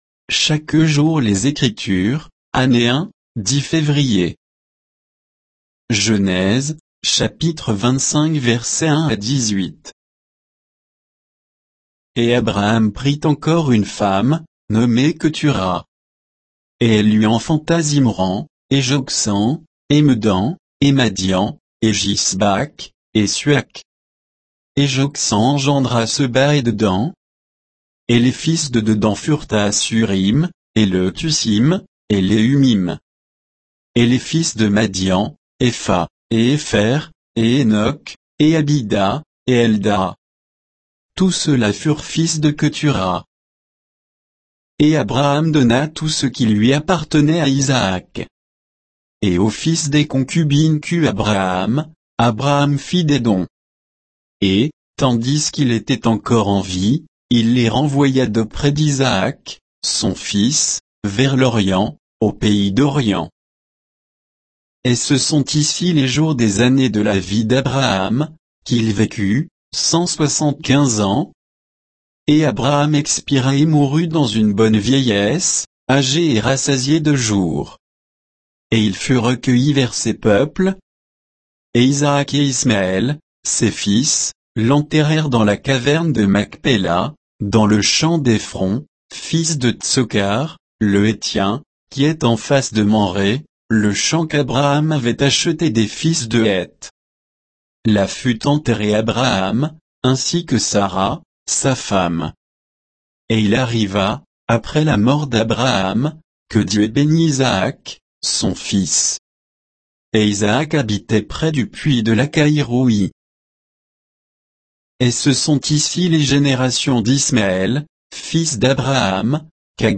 Méditation quoditienne de Chaque jour les Écritures sur Genèse 25